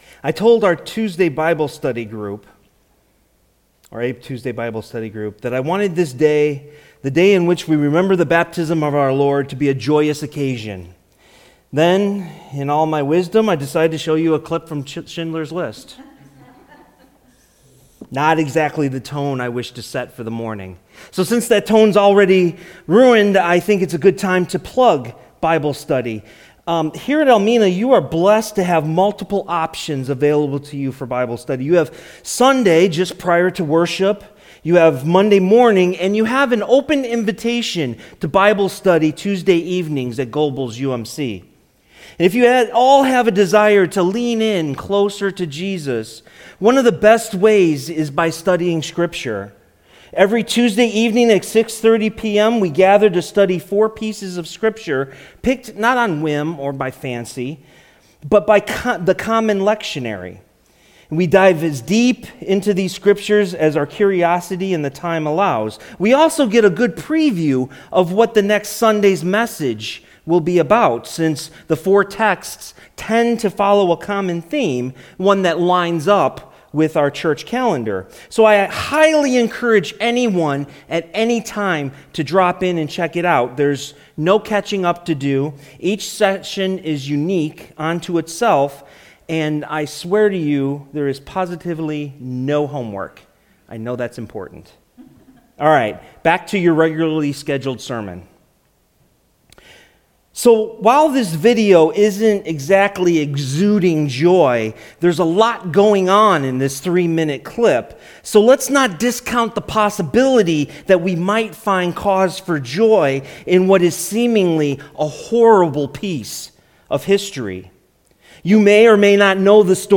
sermon-1-12-20.mp3